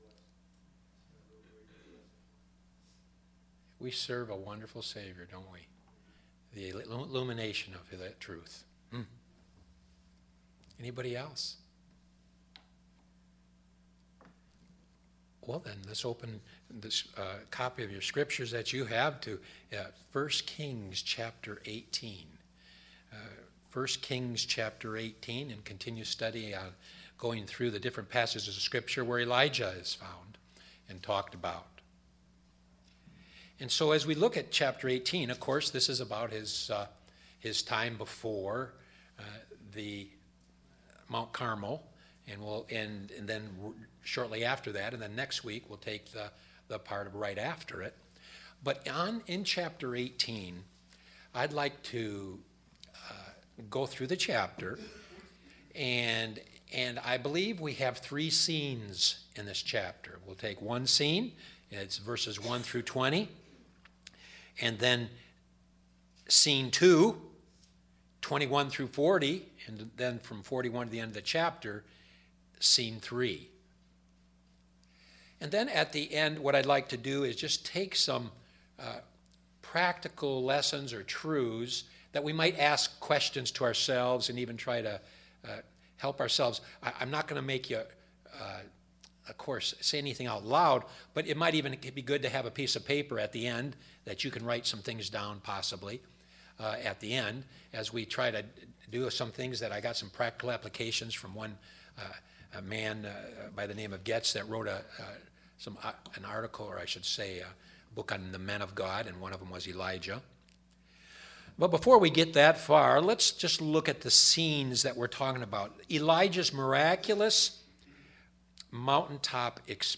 Sermons (Page 5) – Colchester Bible Baptist Church
AM Service